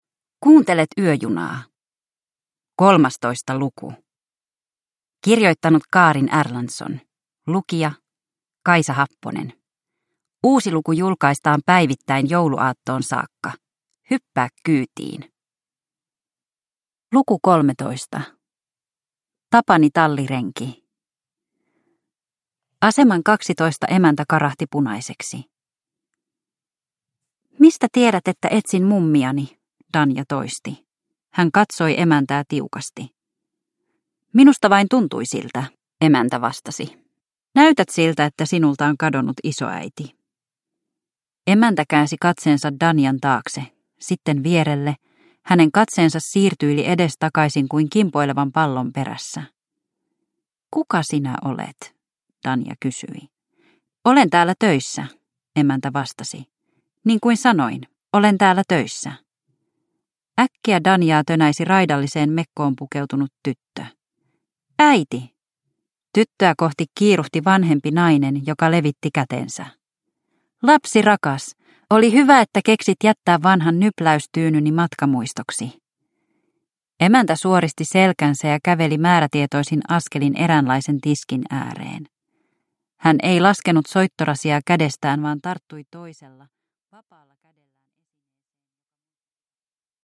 Yöjuna luku 13 – Ljudbok